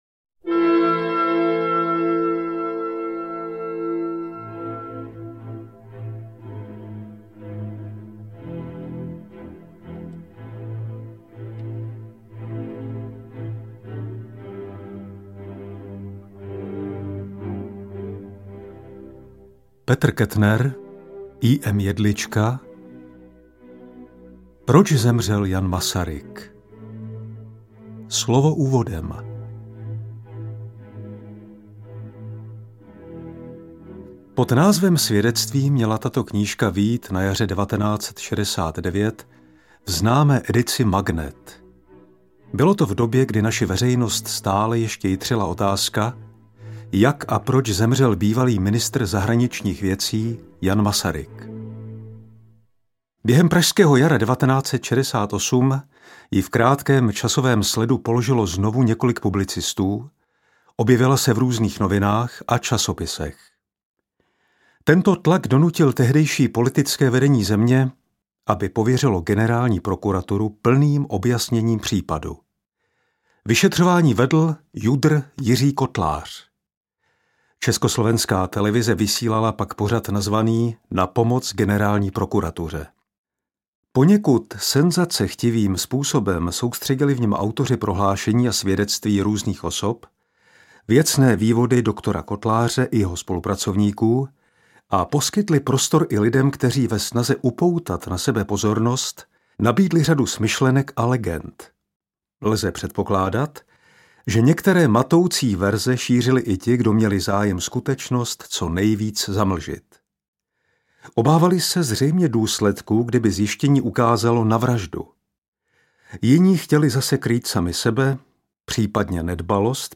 • AudioKniha ke stažení Jedlička, Kettner: Proč zemřel Jan Masaryk?
Interpret:  František Kreuzmann